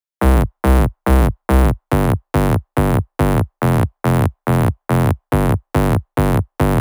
Bassline.wav